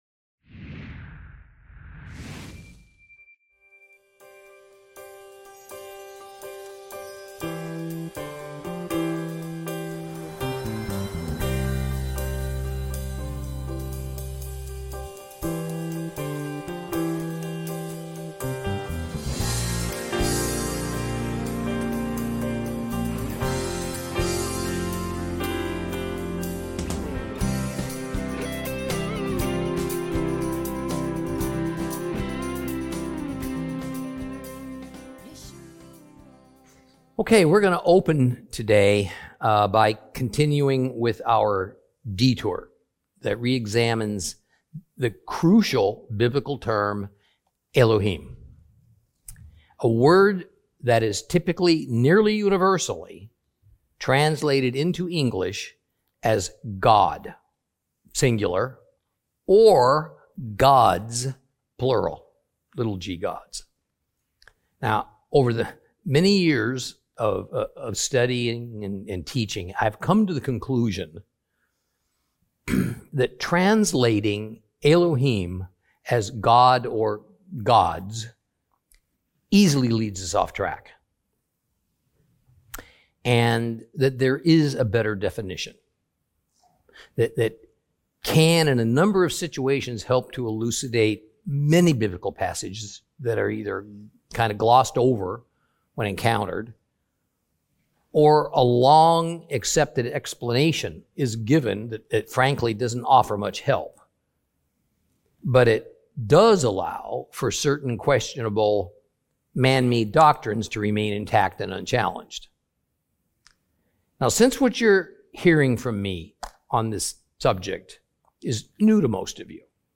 Teaching from the book of Amos, Lesson 11 Chapter 6 continued 2.